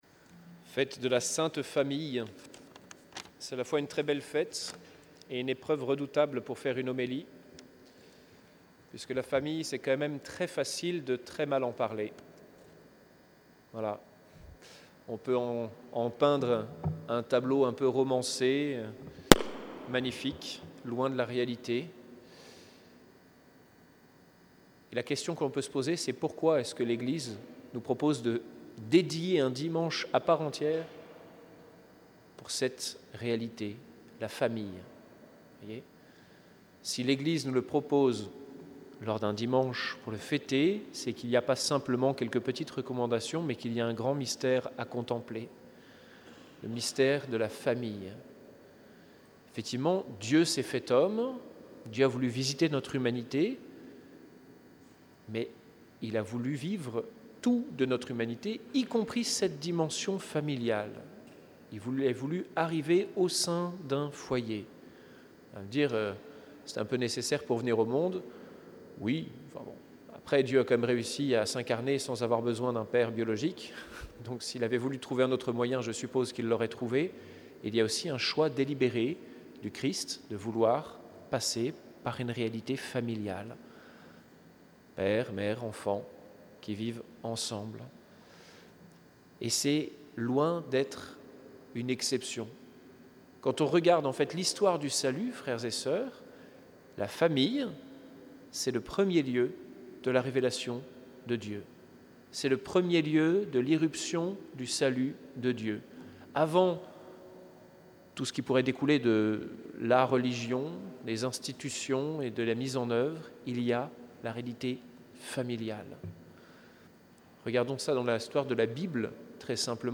ICI, homélie du jour de la Sainte Famille - 28 décembre 2025